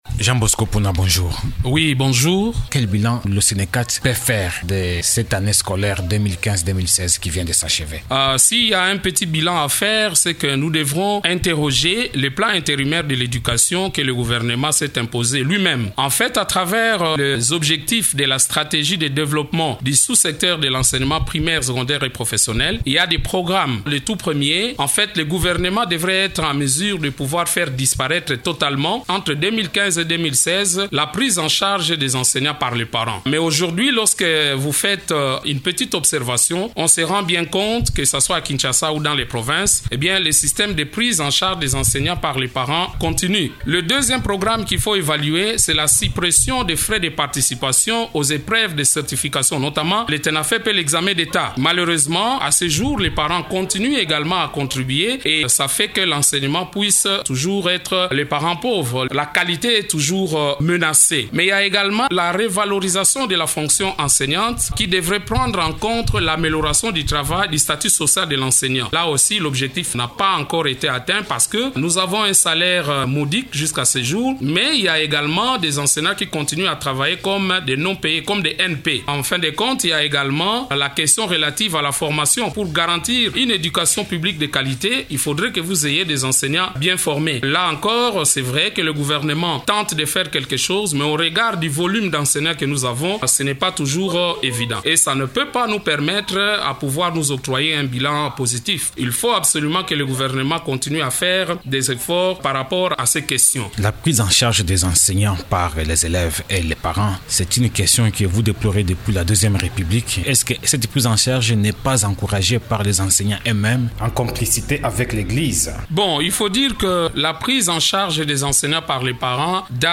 Invité de Radio Okapi mardi 5 juillet, ce syndicaliste estime que le gouvernement congolais a échoué dans l’atteinte des objectifs du plan intérimaire de l’éducation qu’il s’est imposé.